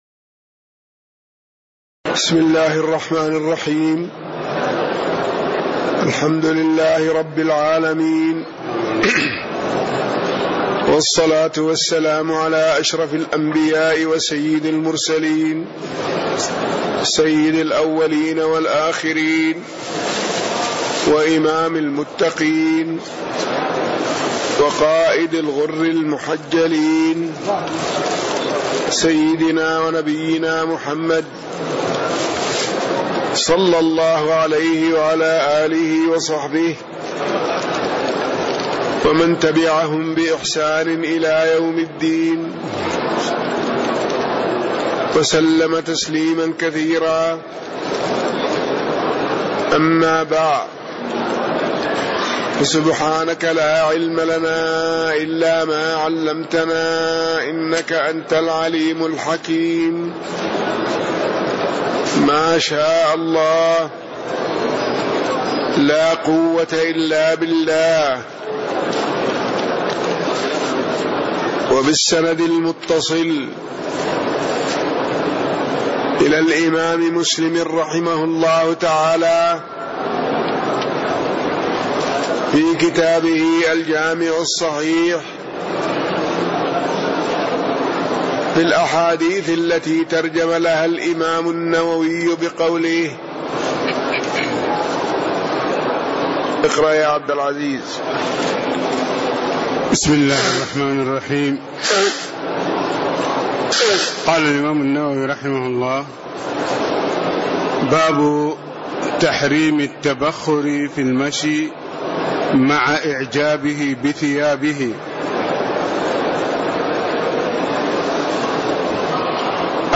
تاريخ النشر ١٠ شوال ١٤٣٦ هـ المكان: المسجد النبوي الشيخ